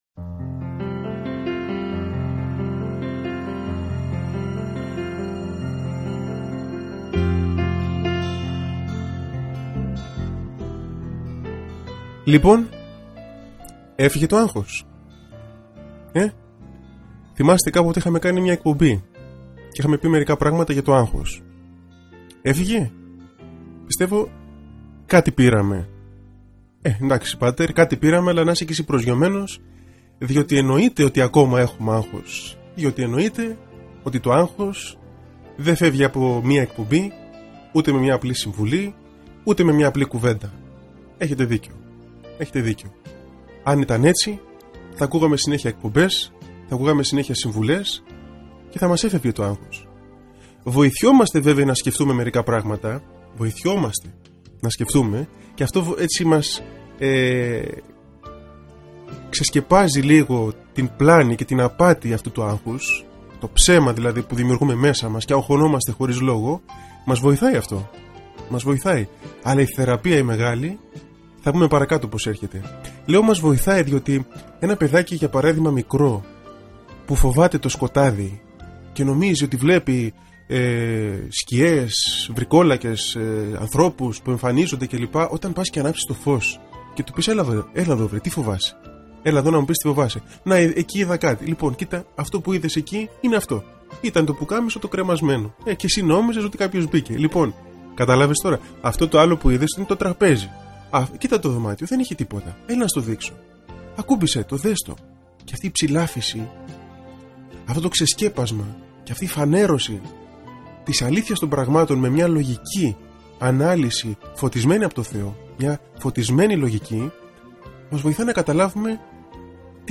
Τρεις ηχογραφημένες ραδιοφωνικές εκπομπές